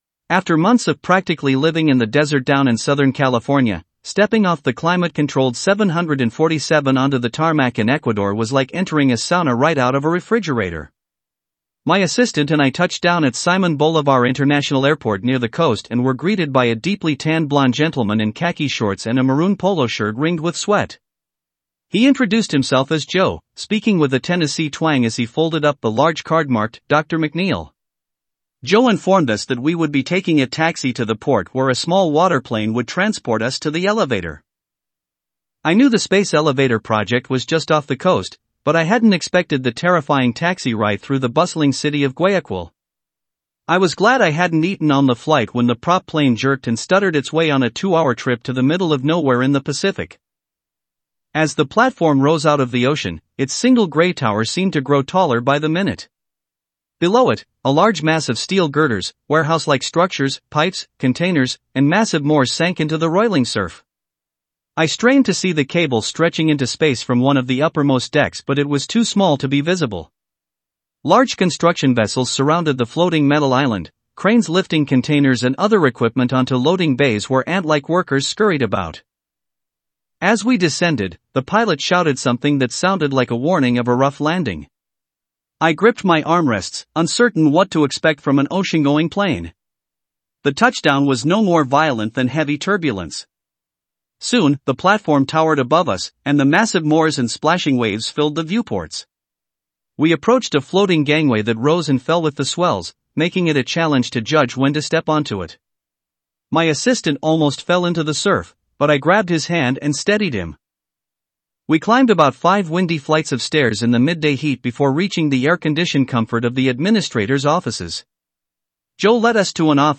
And to the right is a short clip of my Full audiobook of The Mountains Genesis.